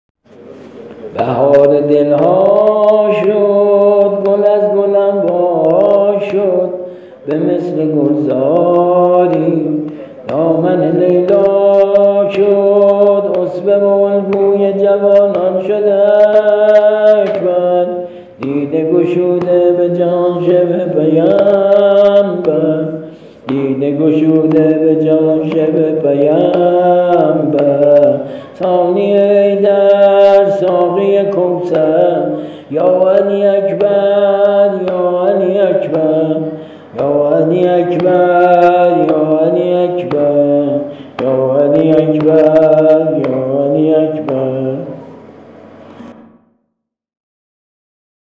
ملودی و سبک و آهنگ جدید